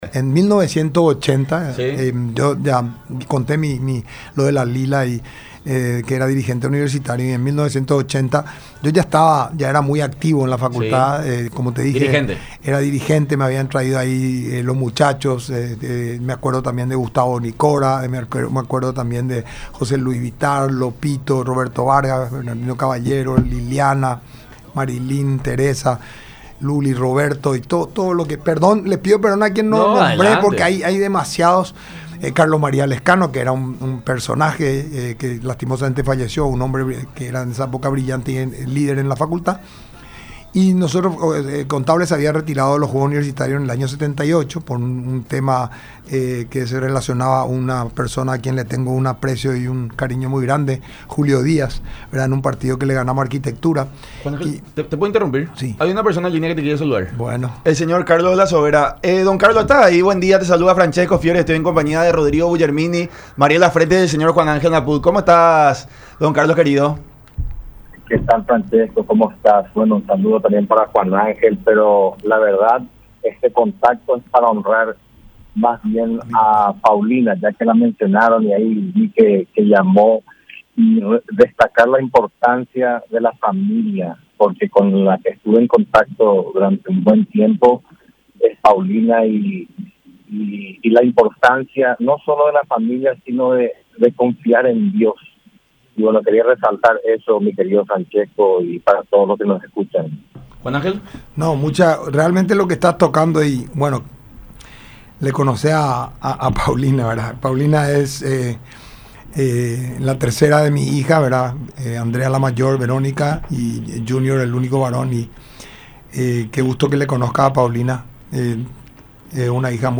Napout estuvo de visita en el programa “La Unión Hace la Fuerza”, a través de radio la Unión y Unión TV, habló sobre los 91 meses pasó en la cárcel lejos de su familia. Indicó que por todo lo que vivió en los últimos años poco y nada le importa las críticas que recibe.